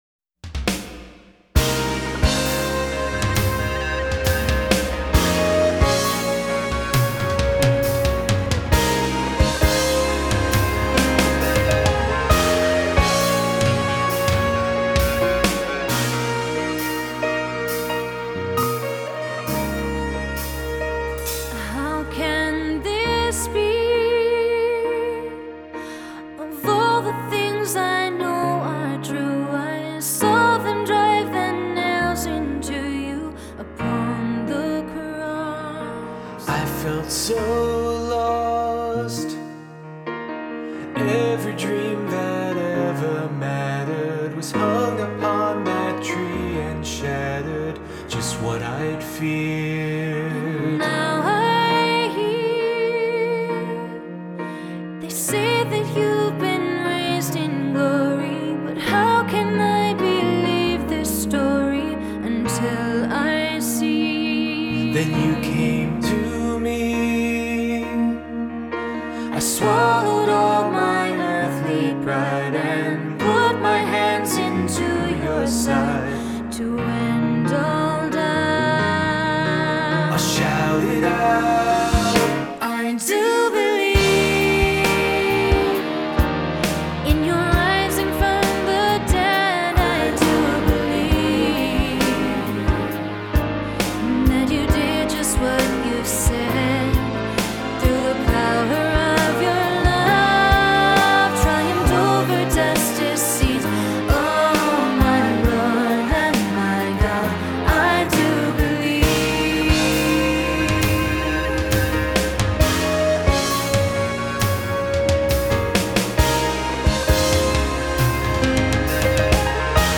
Voicing: Three-part equal; Cantor; Assembly